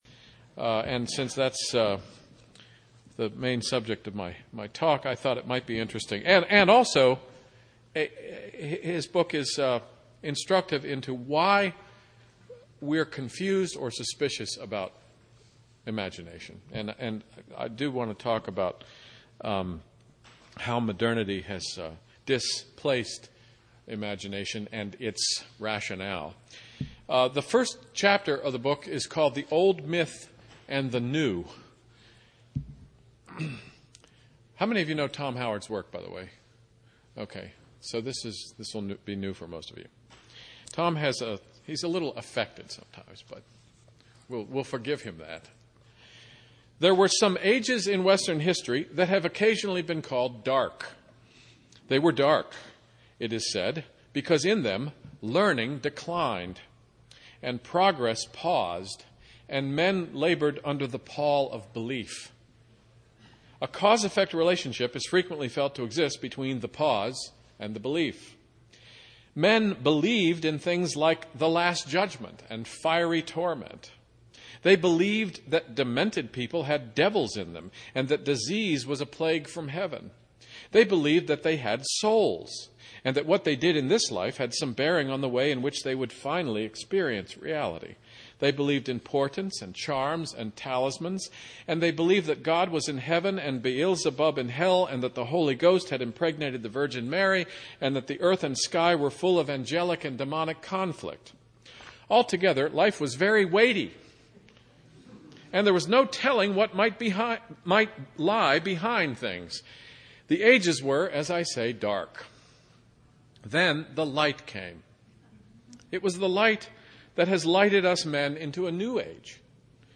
2008 Workshop Talk | 0:56:36 | All Grade Levels, Literature